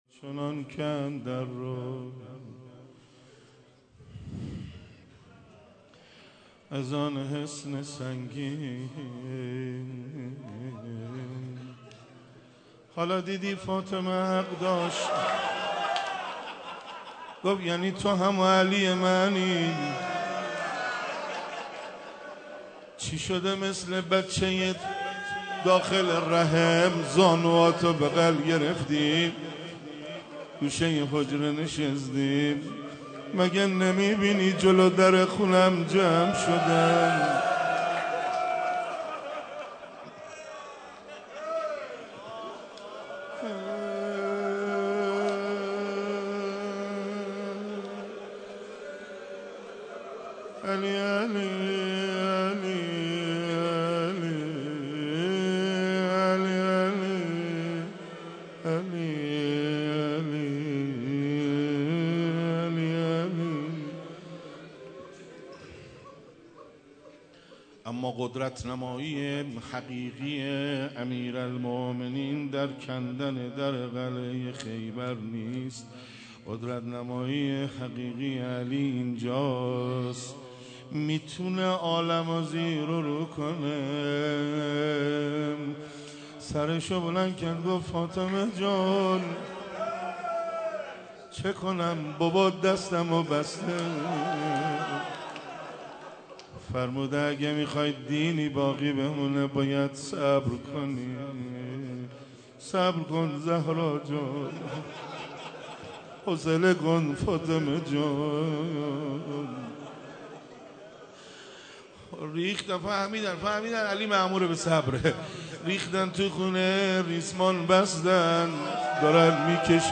مدح و روضه امیرالمومنین(ع)